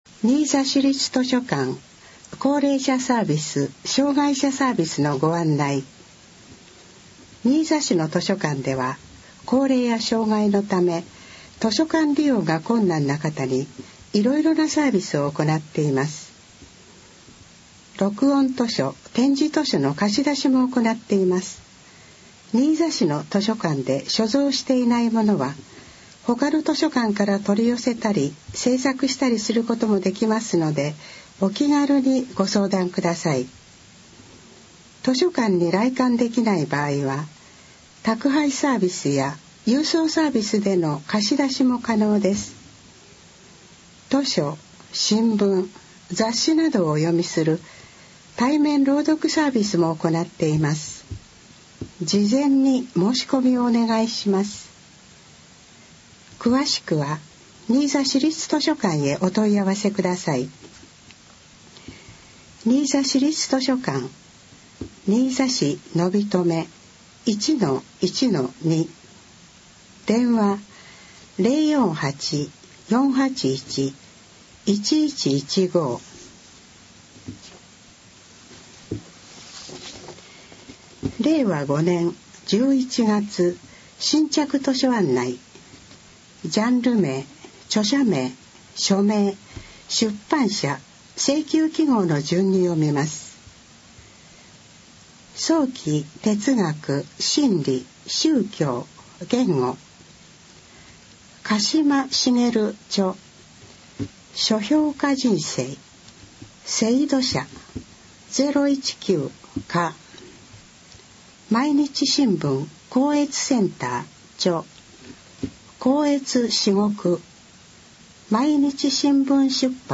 新着図書案内（音声版）は、図書館朗読ボランティアグループ「こだま」の皆さんが、 「図書館だより」の新着図書案内を朗読し、CDに録音しています。